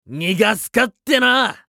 熱血系ボイス～戦闘ボイス～